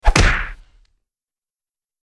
Âm thanh Cú Đấm (Bốp…)
Tiếng Cú Đánh hạ gục kẻ thù Aaa…. Tiếng Đánh vào cơ thể Bịch…
Thể loại: Đánh nhau, vũ khí
am-thanh-cu-dam-bop-www_tiengdong_com.mp3